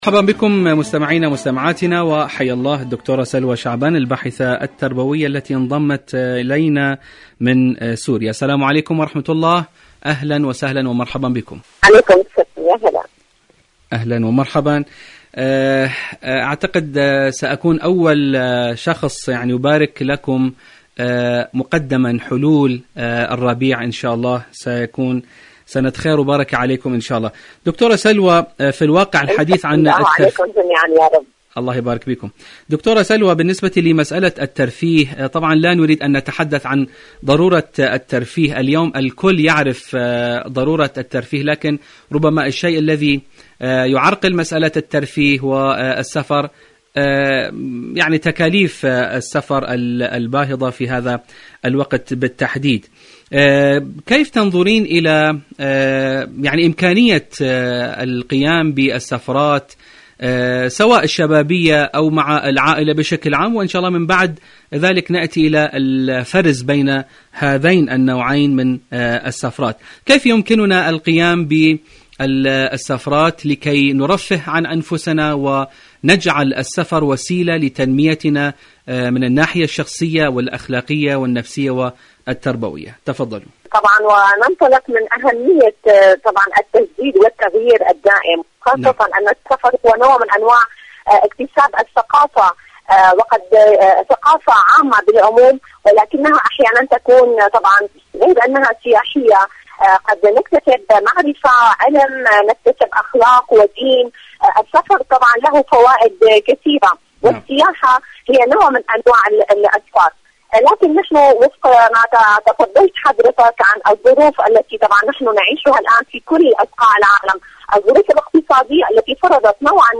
مقابلات برامج إذاعة طهران العربية برنامج دنيا الشباب الشباب الأهل دنيا الشباب الأصدقاء مقابلات إذاعية شاركوا هذا الخبر مع أصدقائكم ذات صلة دور العلاج الطبيعي بعد العمليات الجراحية..